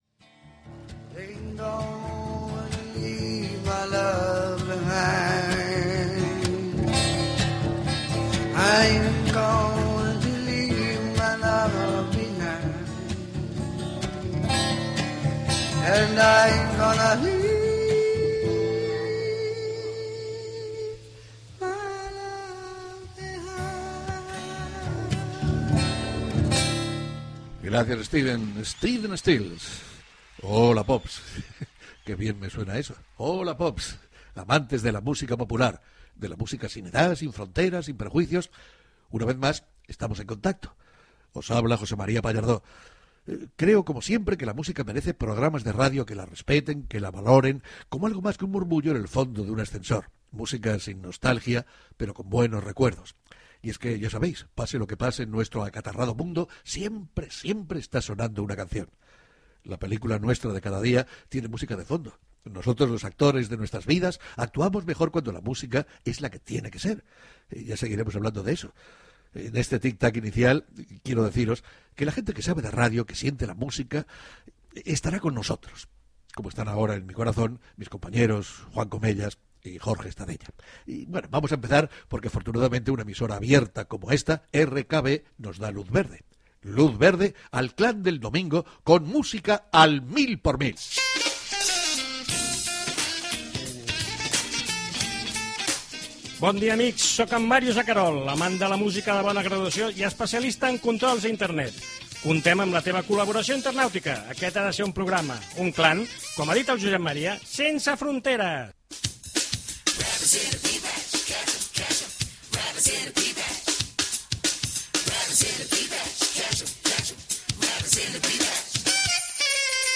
Tema musical, presentació del primer programa amb diversos temes musicals i anuncis de la presència del programa a Internet
Musical